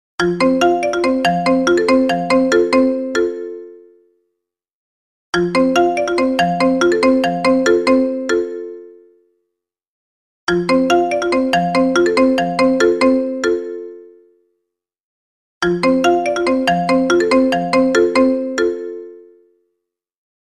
Маримба - рингтон
Отличного качества, без посторонних шумов.